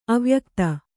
♪ avyakta